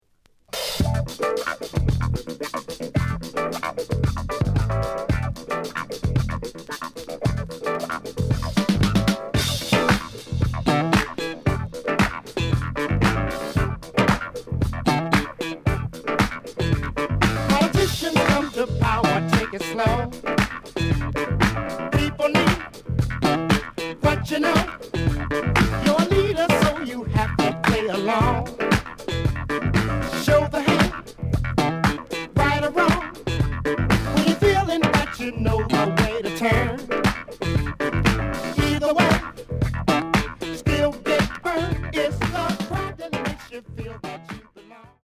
The listen sample is recorded from the actual item.
●Format: 7 inch
●Genre: Disco